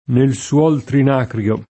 trinacrio [ trin # kr L o ]